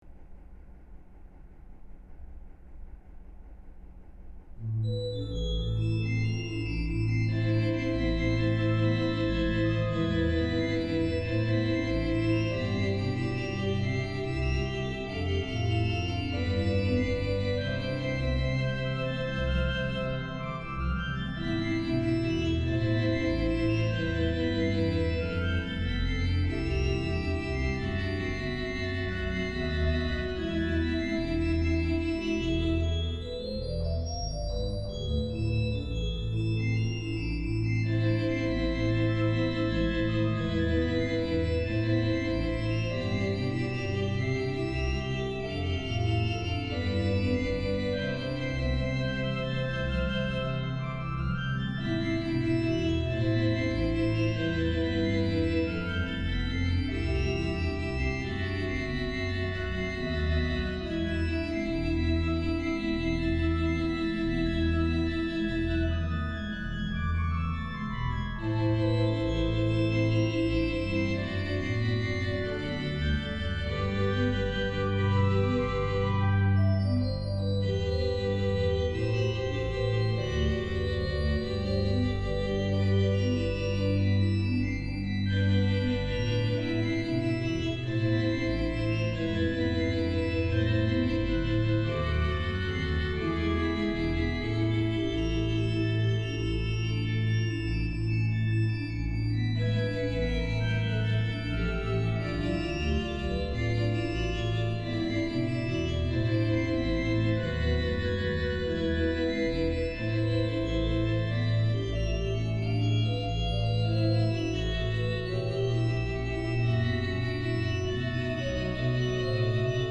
Orgue